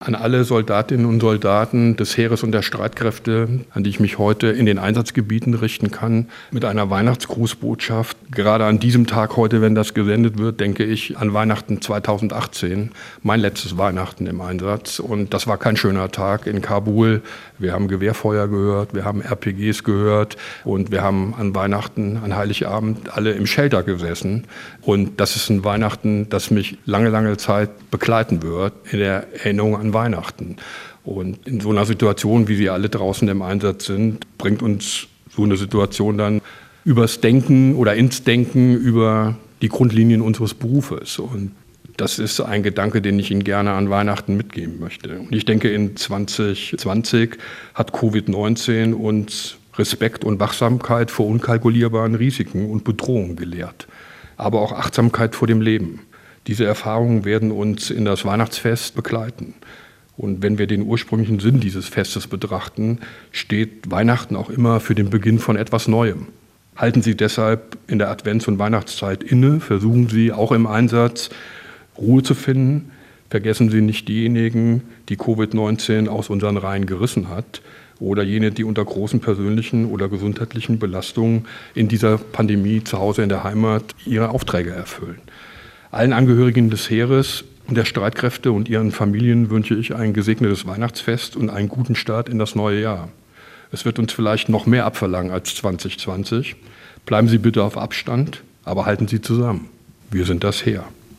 Weihnachtsgrüße vom Inspekteur des Heeres